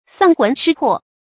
喪魂失魄 注音： ㄙㄤˋ ㄏㄨㄣˊ ㄕㄧ ㄆㄛˋ 讀音讀法： 意思解釋： 原義為失去了魂魄。形容極度驚慌、恐懼。